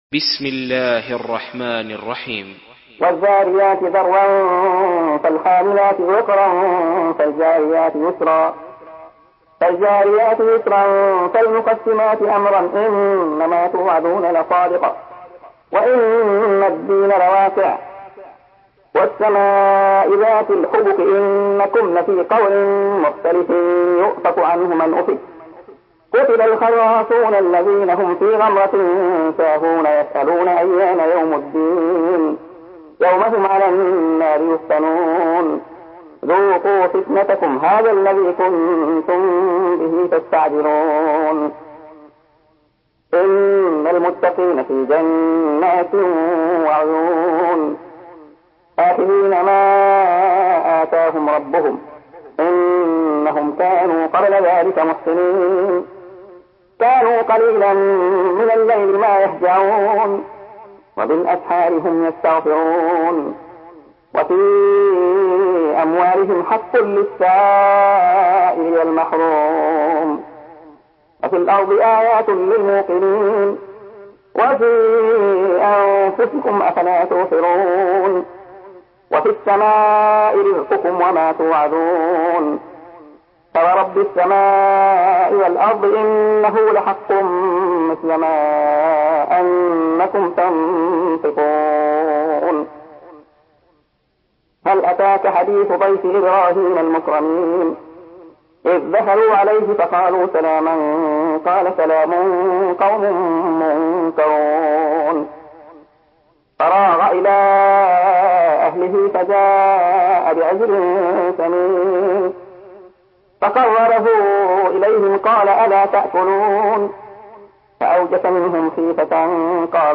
Surah Ad-Dariyat MP3 by Abdullah Khayyat in Hafs An Asim narration.
Murattal Hafs An Asim